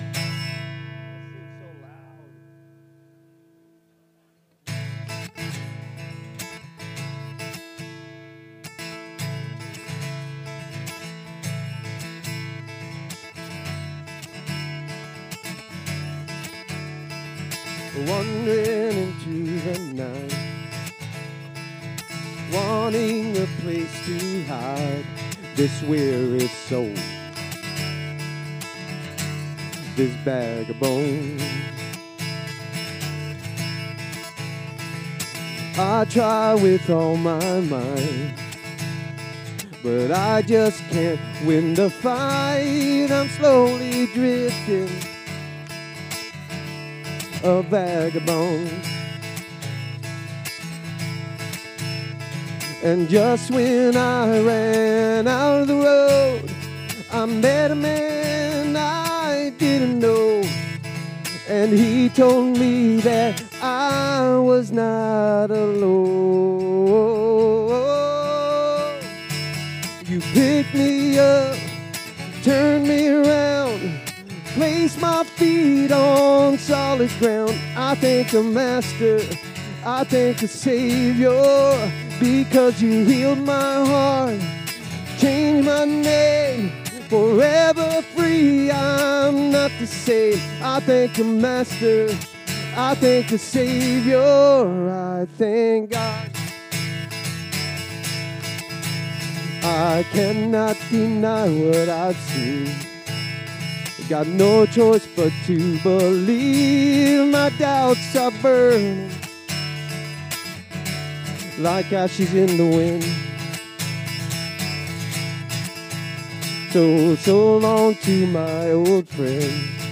Download Download Reference Acts 4:34-5:11 Sermon Notes Click here for notes 250223.pdf SERMON DESCRIPTION The movement was moving and things were looking good until this moment.